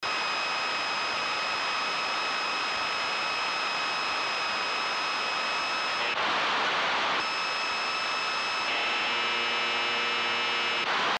Así que me dispuse a poner a grabar al A1-WSX durante un rato mientras buscaba un dial en onda corta donde oirlo, y ocurrió.
Aquí podéis oir lo que registré con la radio.